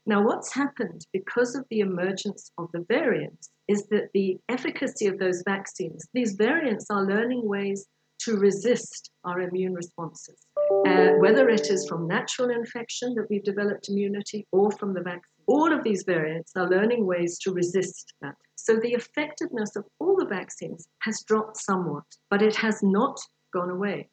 During a parliamentary briefing this week, Rees said getting vaccinated would also help reduce pressure on the country’s healthcare system.